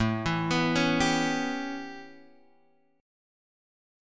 A9 chord {5 x 2 4 2 3} chord